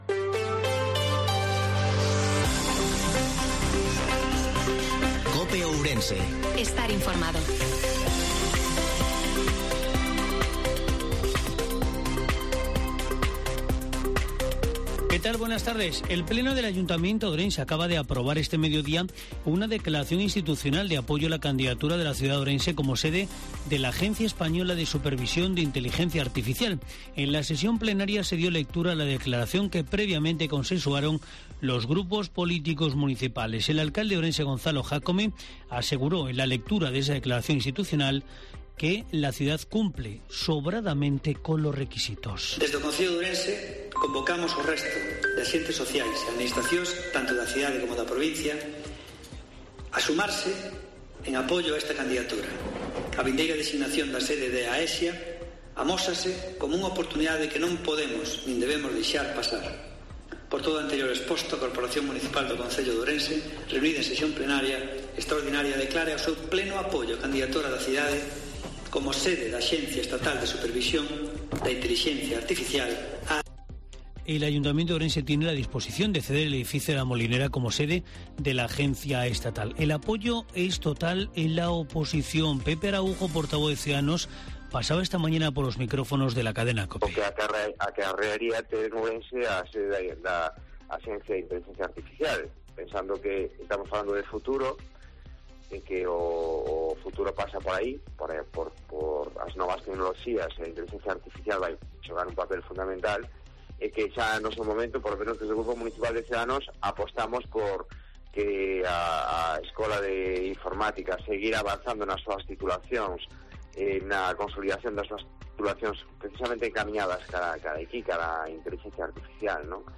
INFORMATIVO MEDIODIA COPE OURENSE-12/09/2022